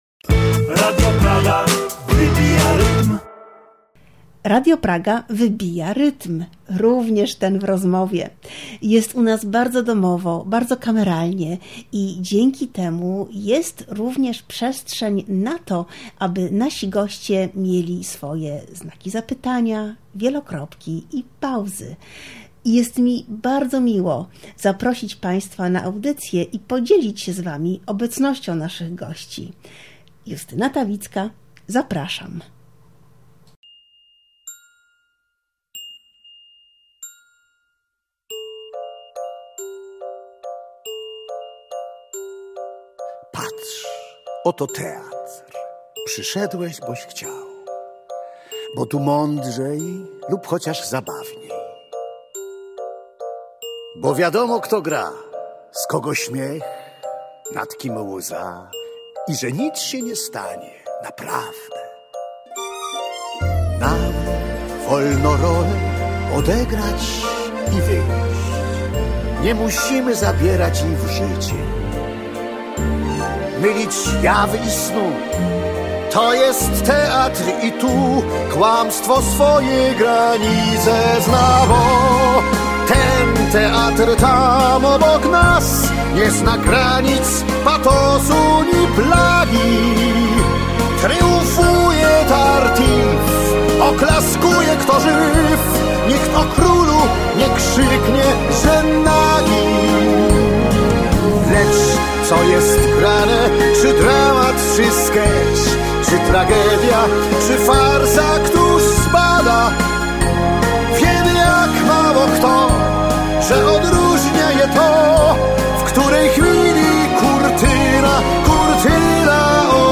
Wspaniały to duet do rozmowy.